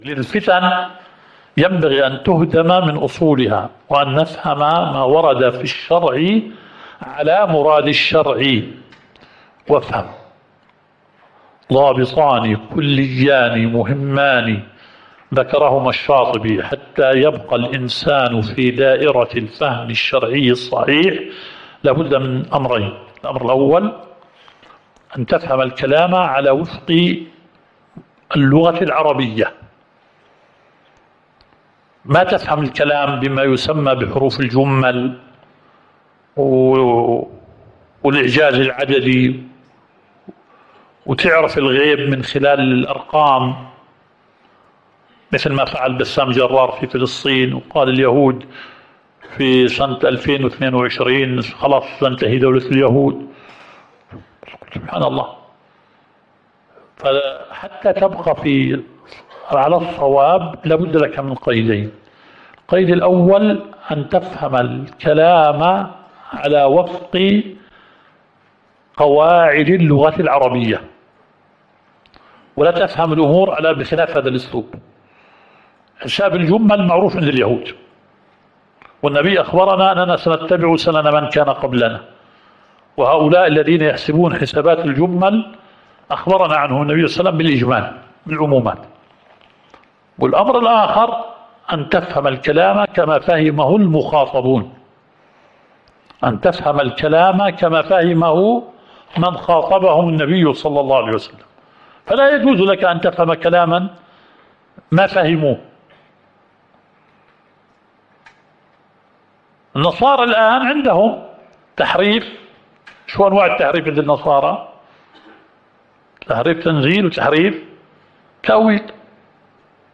الدورة الشرعية الثالثة للدعاة في اندونيسيا – منهج السلف في التعامل مع الفتن – المحاضرة الرابعة.